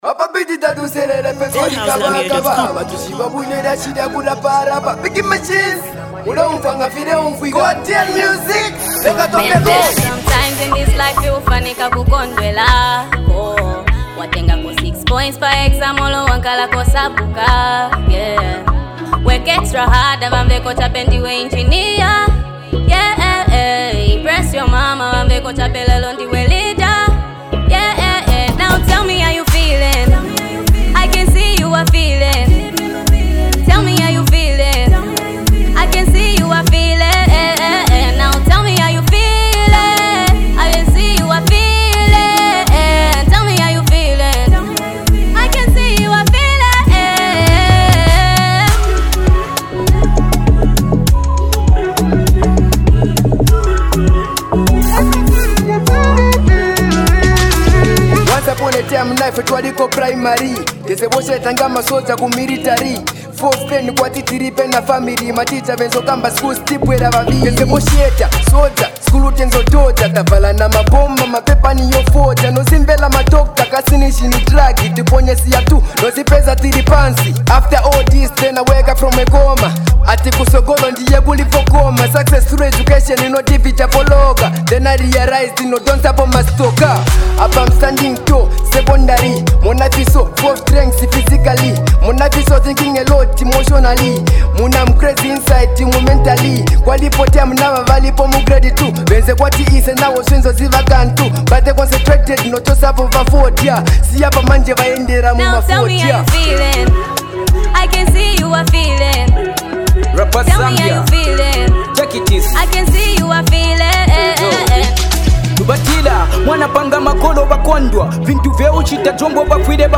” a powerful blend of rap and soulful vocals.